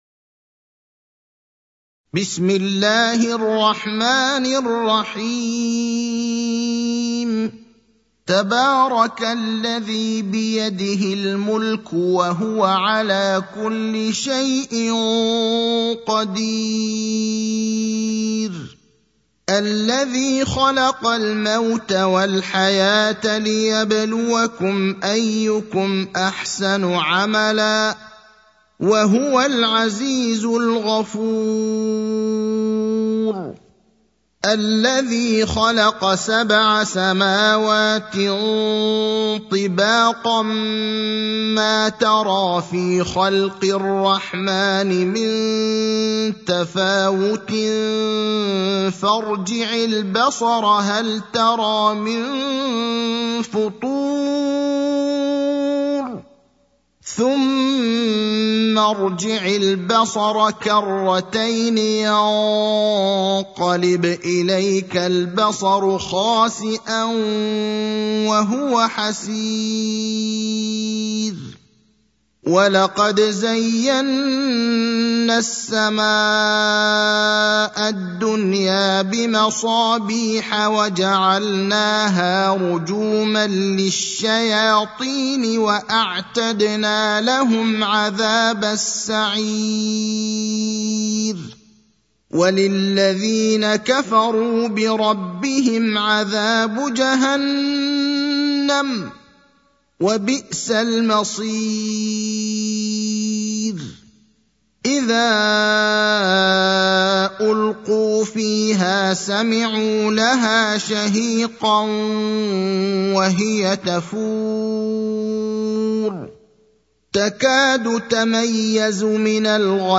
المكان: المسجد النبوي الشيخ: فضيلة الشيخ إبراهيم الأخضر فضيلة الشيخ إبراهيم الأخضر الملك (67)